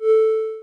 Techmino/media/effect/chiptune/spin_0.ogg at dacefb2b01bd008d29deda8780cb9177b34fc95d
spin_0.ogg